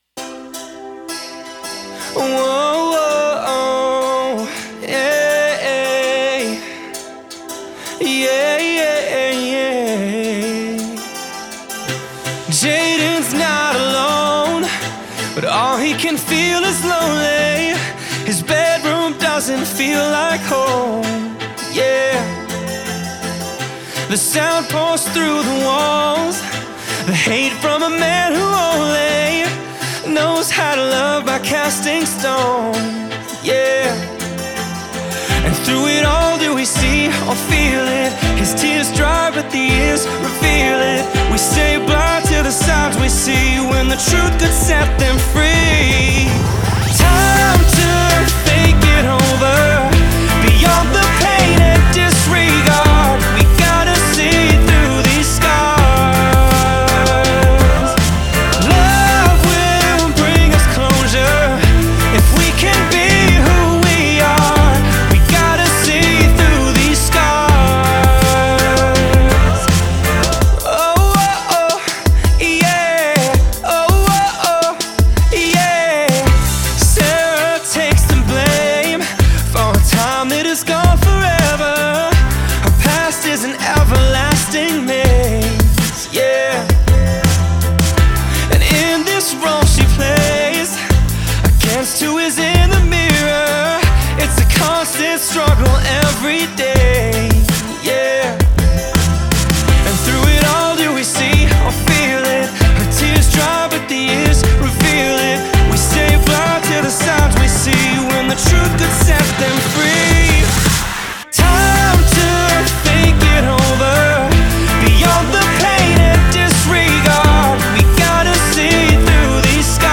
Genre: Pop-Rock, Soft-Rock, Acoustic.